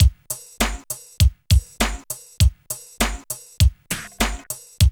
22 DRUM LP-L.wav